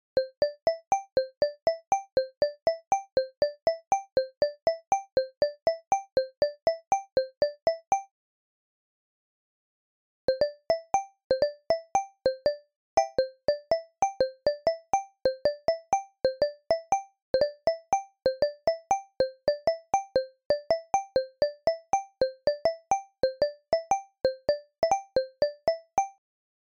A basic pattern is played on the DN2, seq’d by DN2. Fine.
Then the same pattern is played on the DN2, seq’d via TV over MIDI cable. Not fine.